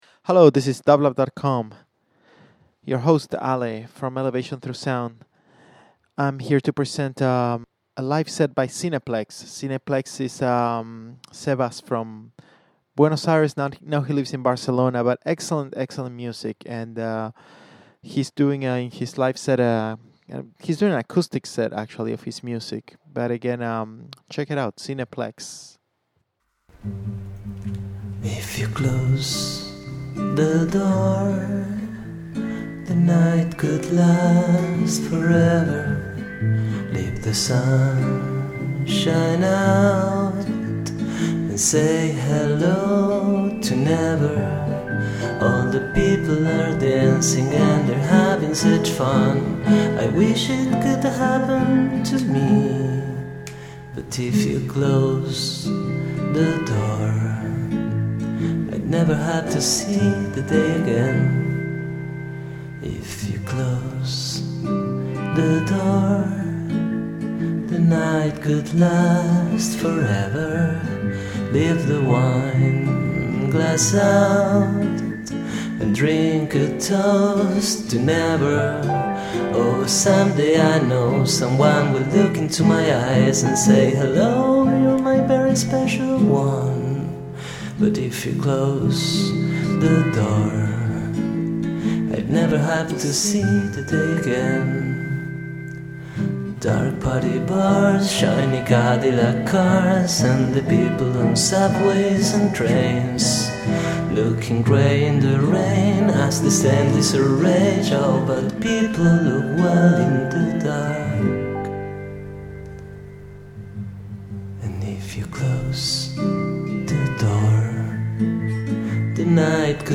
This is a live set by musician and producer
Indie